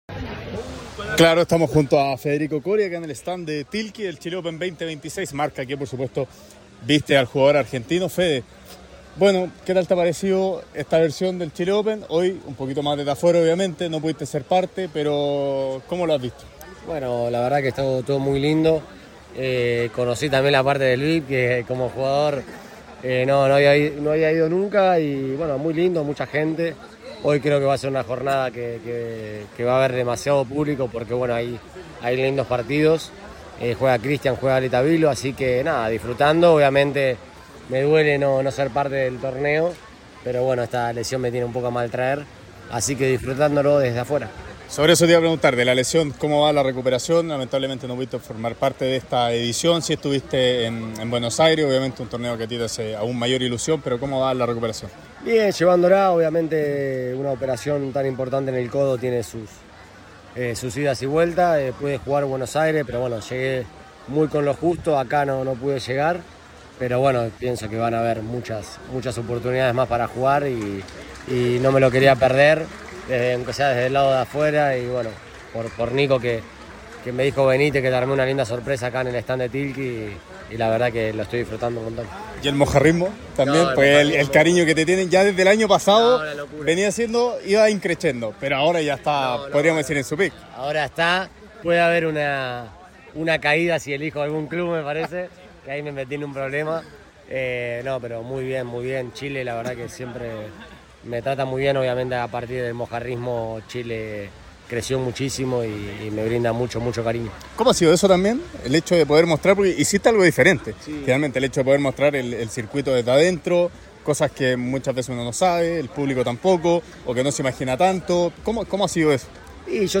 En conversación con ADN Deportes, Federico Coria abordó el desarrollo del Chile Open y también dejó ver su faceta futbolera en plena semana de Superclásico.
Federico Coria, tenista argentino